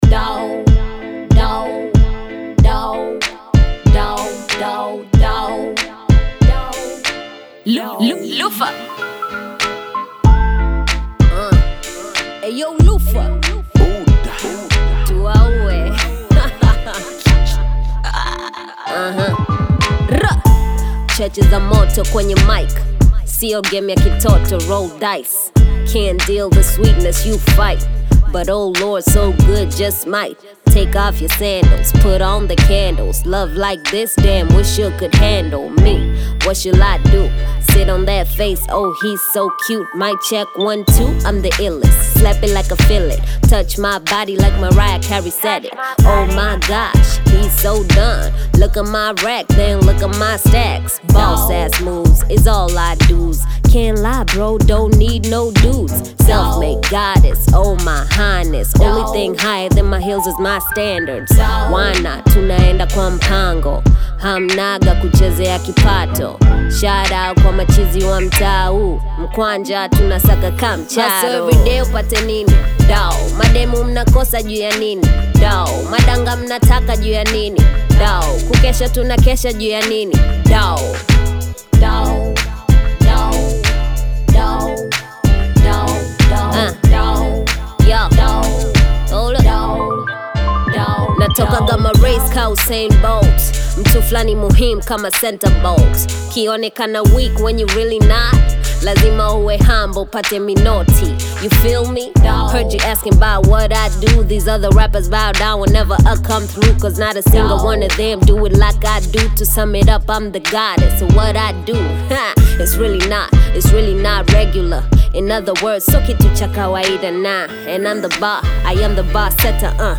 bold Tanzanian hip-hop/Bongo Flava single
confident lyrics and rhythmic beats